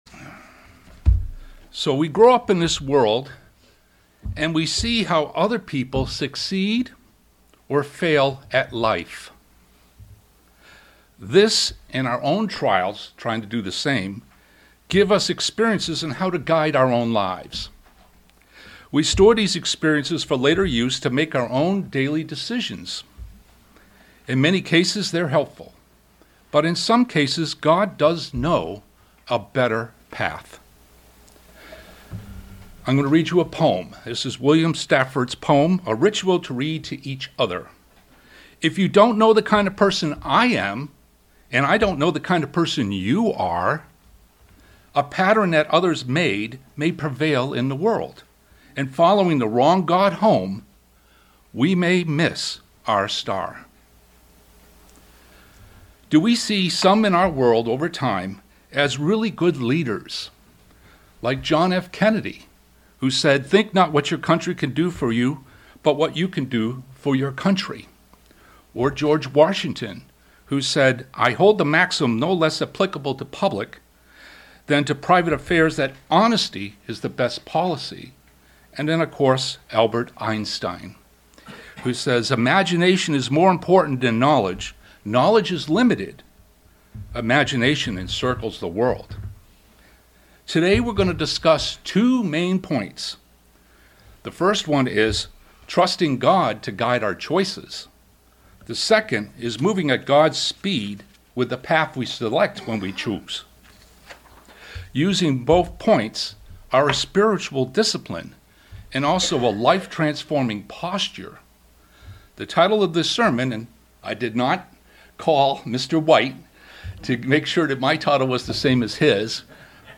A message outlining three ways of learning how to trust and rely on God in our lives.